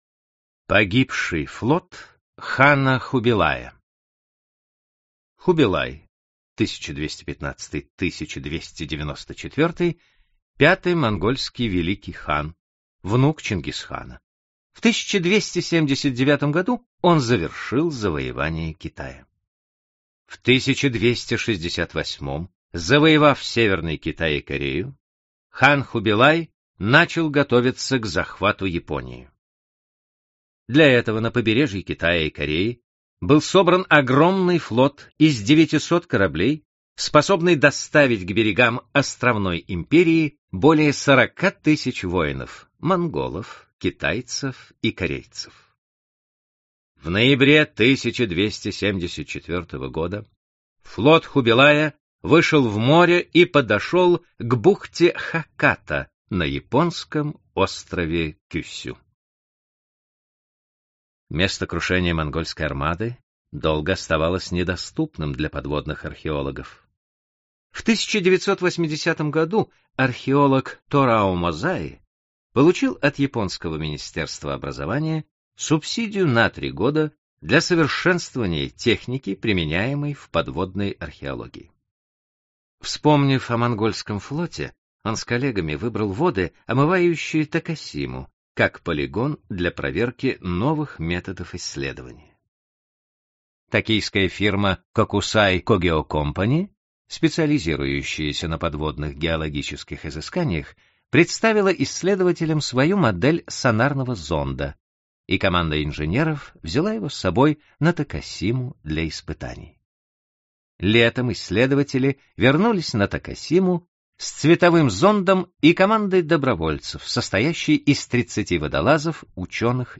Аудиокнига Великие загадки истории. часть 1 | Библиотека аудиокниг